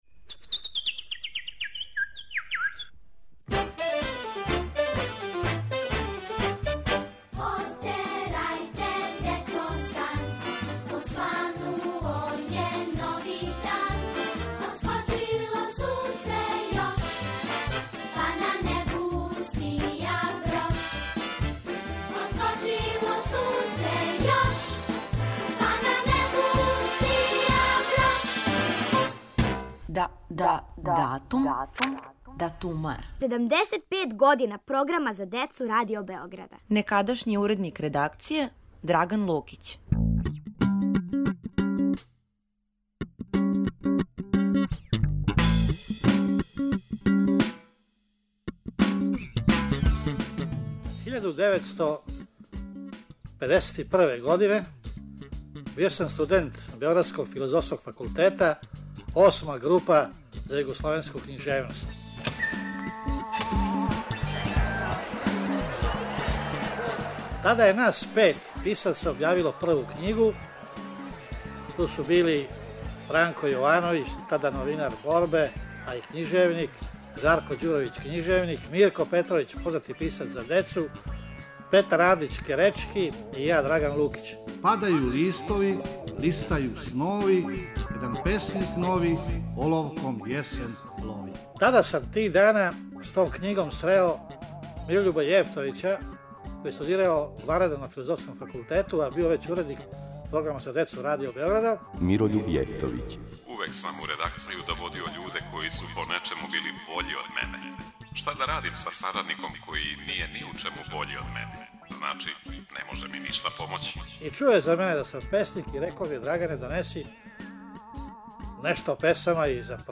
Поводом 75. рођендана Програма за децу и младе, слушате гласове некадашњих уредника редакције.